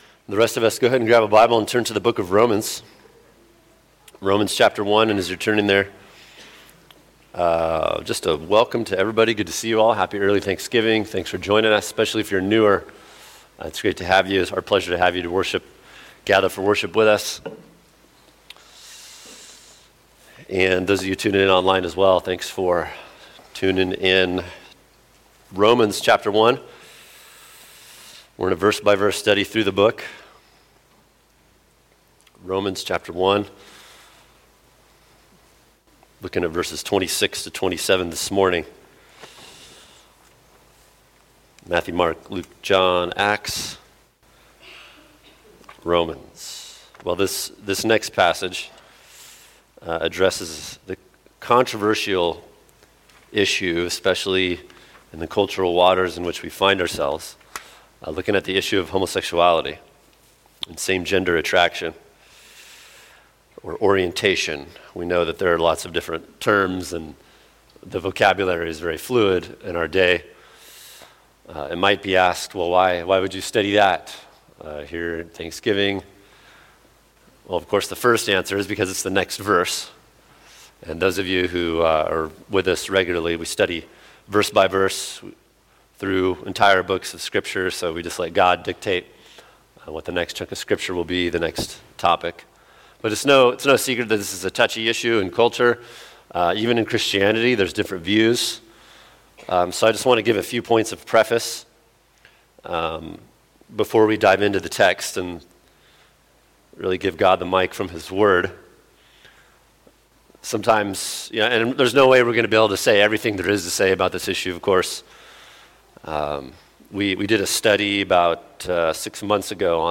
[sermon] Romans 1:26-27 When God Lets You Have Your Way – Part 2 | Cornerstone Church - Jackson Hole